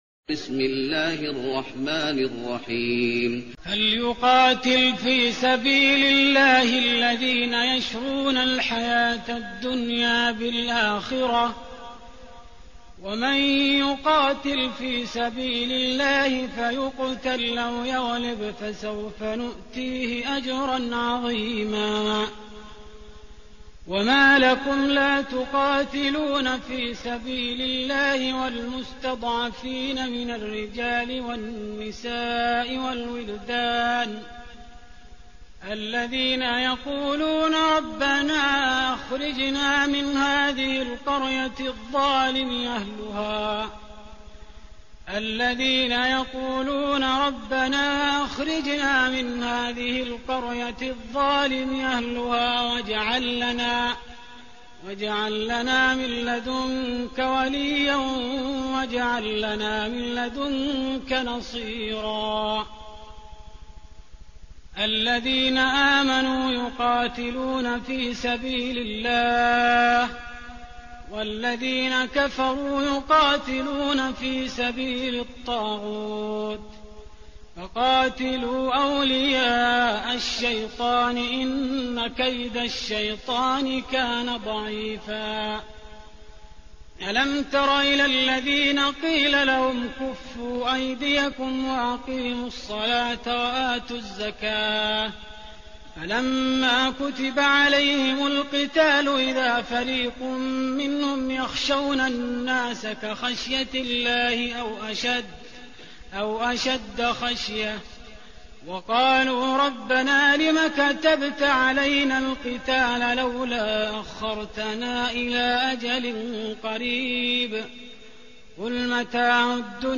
تهجد رمضان 1416هـ من سورة النساء (74-134) Tahajjud Ramadan 1416H from Surah An-Nisaa > تراويح الحرم النبوي عام 1416 🕌 > التراويح - تلاوات الحرمين